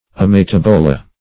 Search Result for " ametabola" : The Collaborative International Dictionary of English v.0.48: Ametabola \Am`e*tab"o*la\, n. pl.
ametabola.mp3